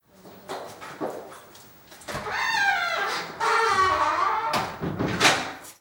Crujir y cerrar una puerta blindada de una casa
puerta
crujir
Sonidos: Hogar